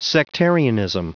Prononciation du mot sectarianism en anglais (fichier audio)
Prononciation du mot : sectarianism